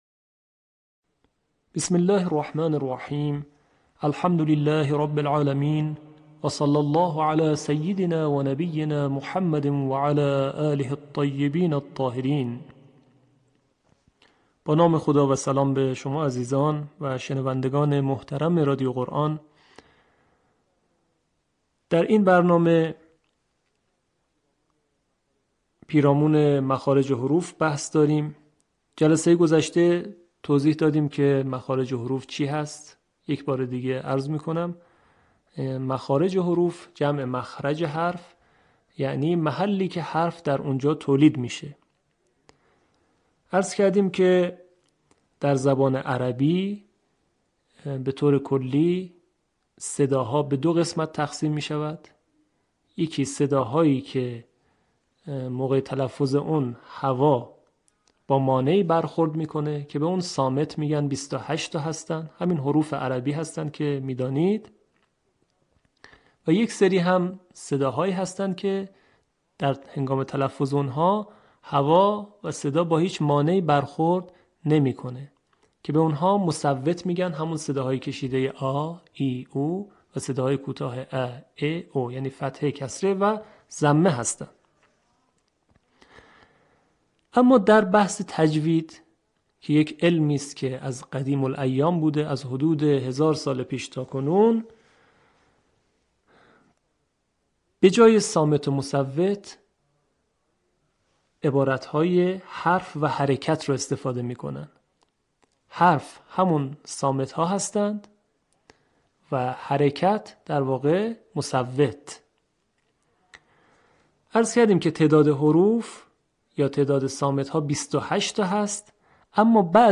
آموزش تجوید